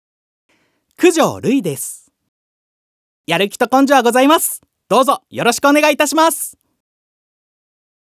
自己紹介